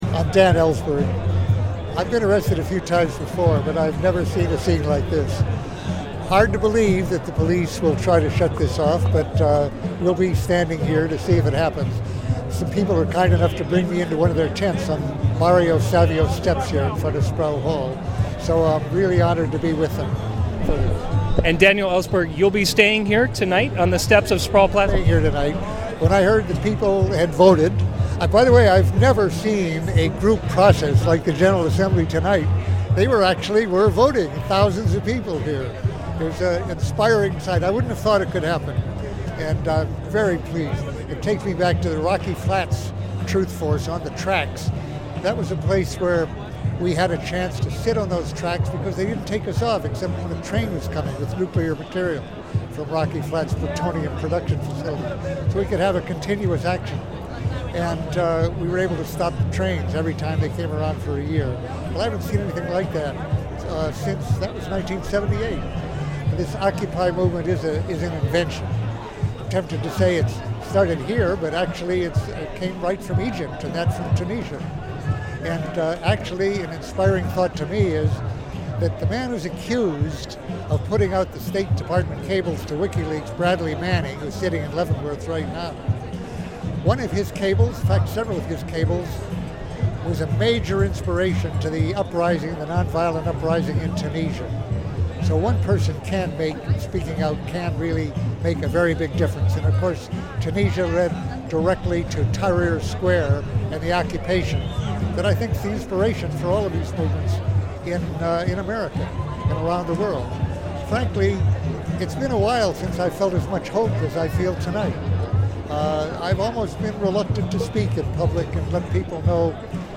He joins students that have just been re-occupying the Sprowl Plaza at the University of California Berkeley, a significant place in the history of the country as the protests against the Vietnam War started exactly there.
Sproul Plaza, Nov. 15, 2011